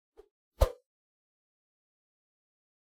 meleeattack-swoosh-light-group02-01.ogg